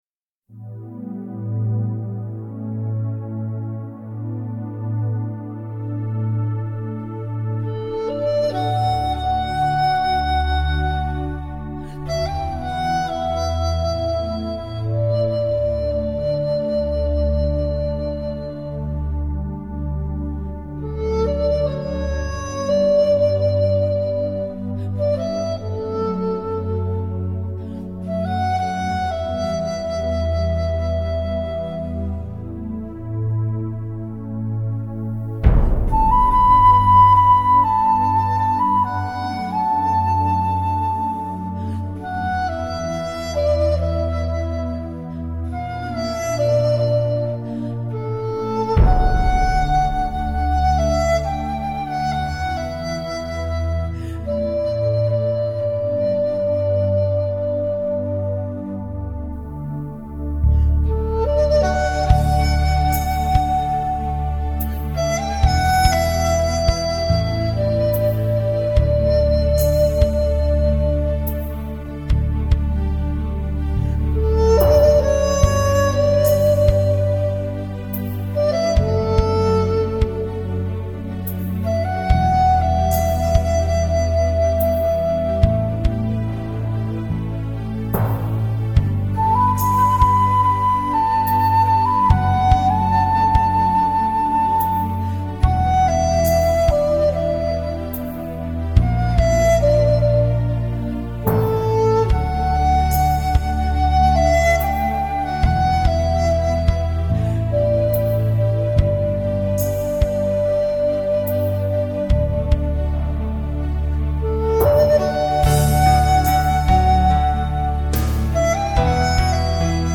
音乐类型：中国民乐